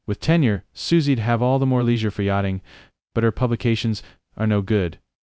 speech-style-transfer text-to-speech voice-cloning